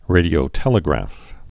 (rādē-ō-tĕlĭ-grăf)